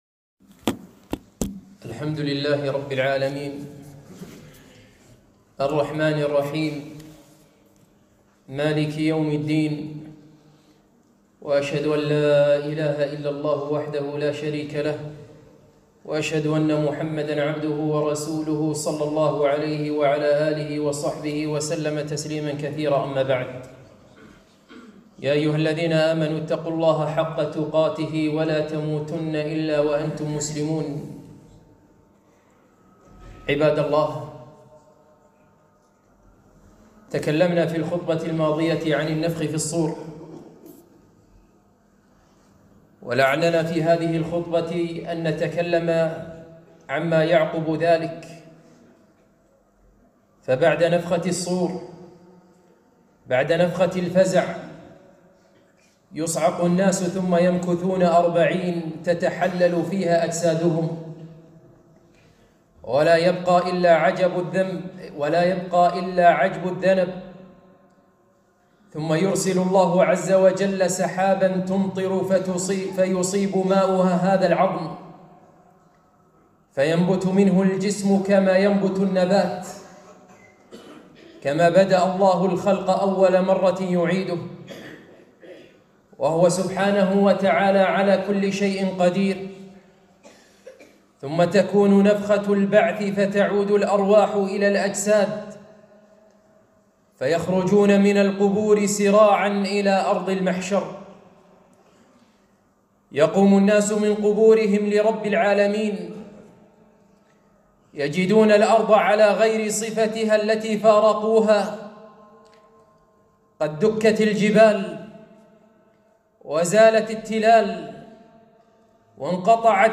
خطبة - أهوال المحشر